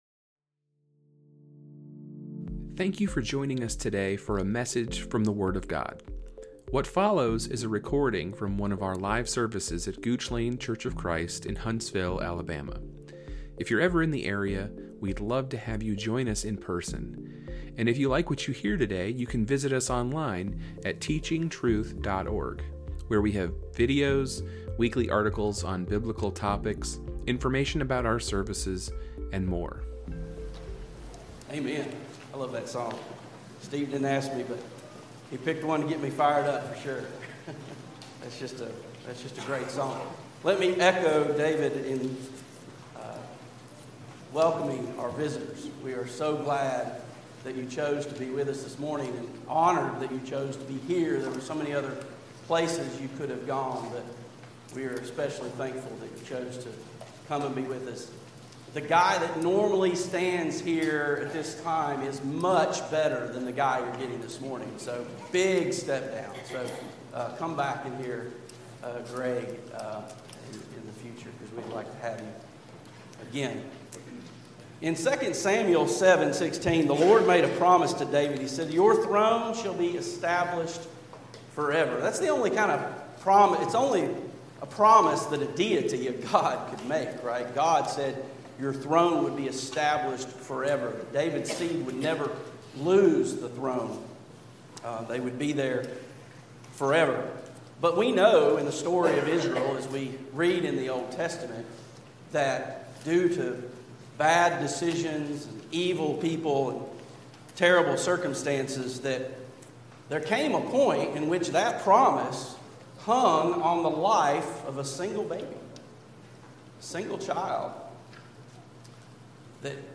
A sermon given on April 6, 2025.